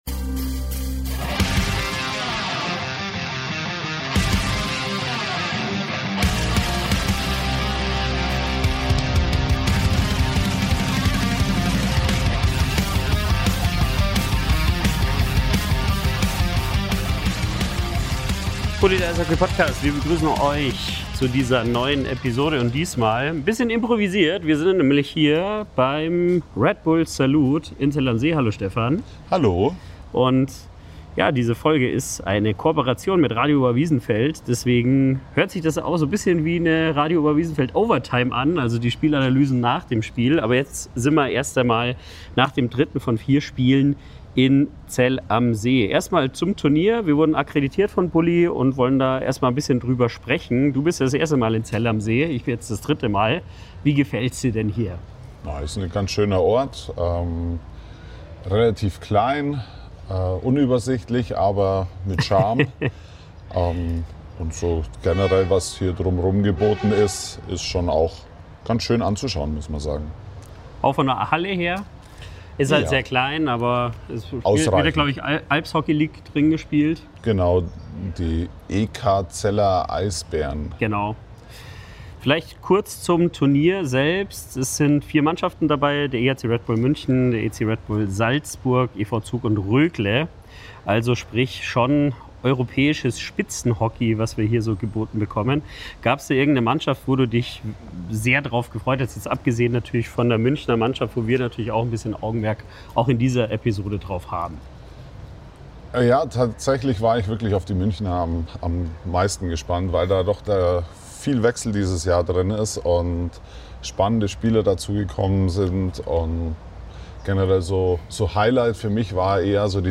S03 - E01 - Live vom Red Bulls Salute in Zell am See